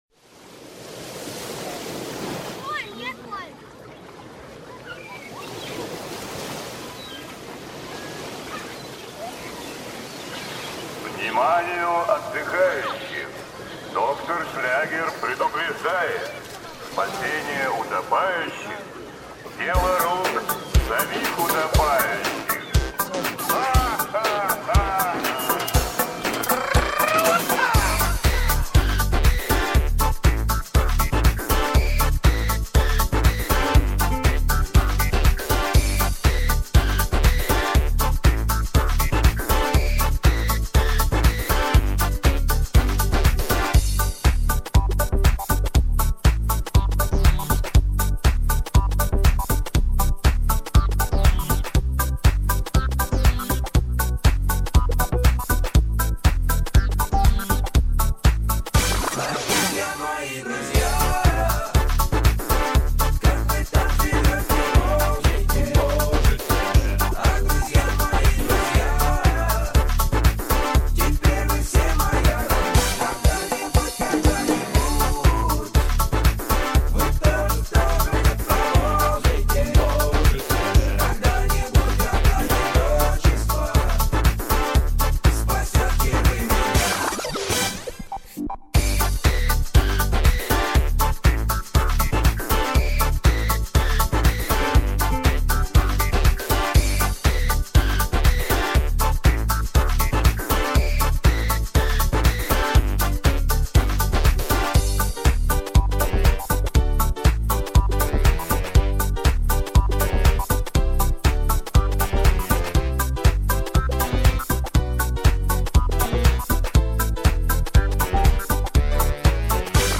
Музыкальные минусовки